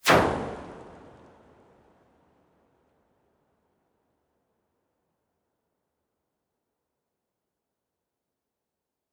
Impact with drips.wav